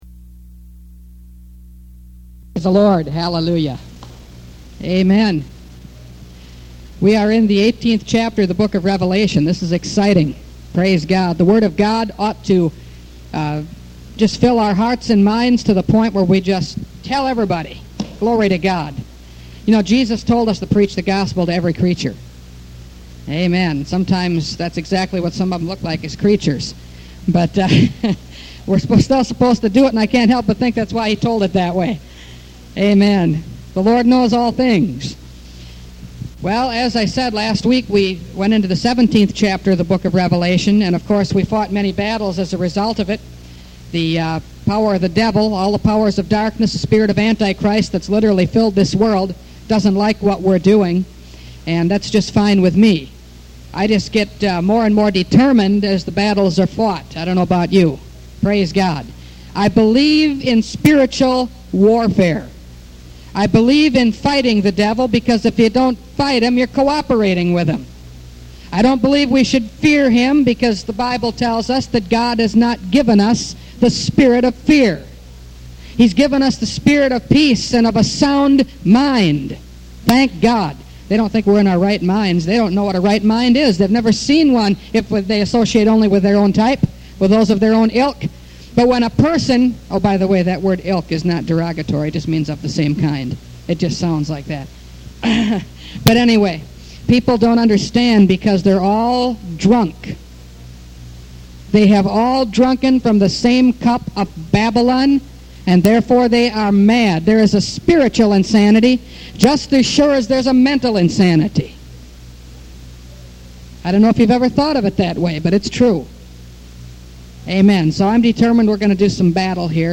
Revelation Series – Part 40 – Last Trumpet Ministries – Truth Tabernacle – Sermon Library